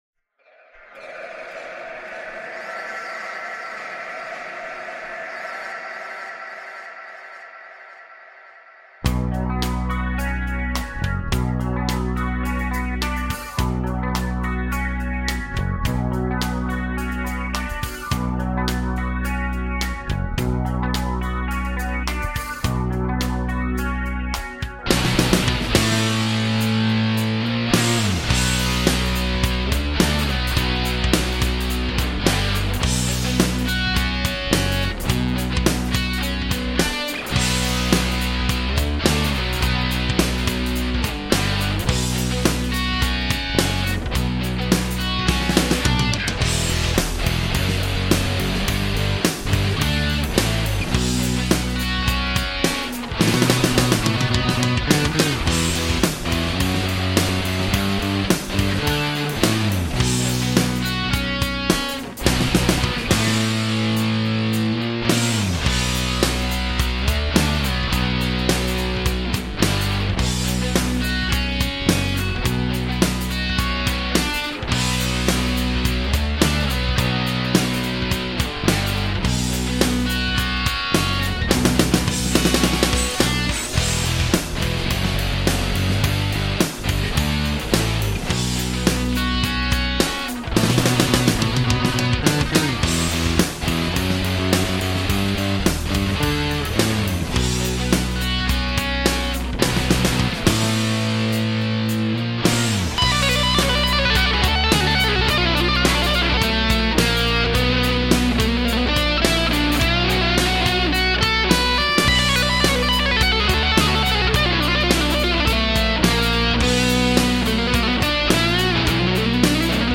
Definitely needs more gain though.
Since there are 6 guitar tracks done with 3 different guitars I'm not going to break down the settings that I used. I thought that I would try something different so I played the lead guitar without a pick.